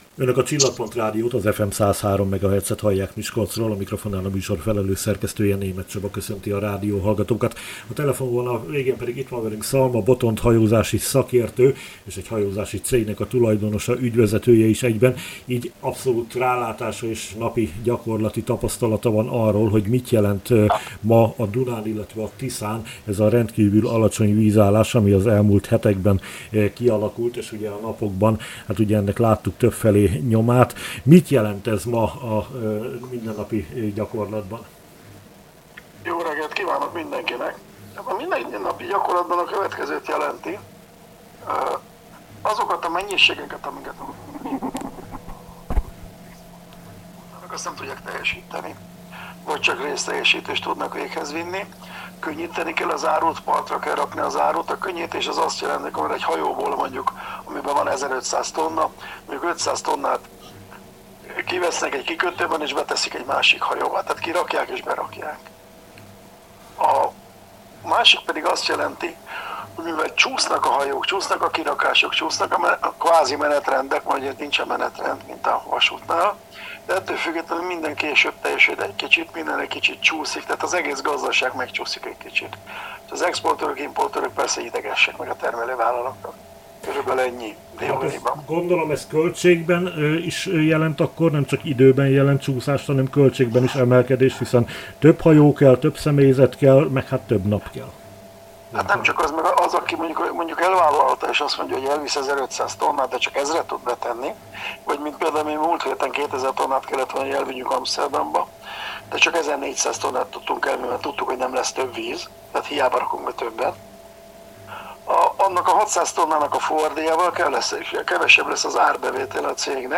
hajózási szakértőt kérdeztük a témában.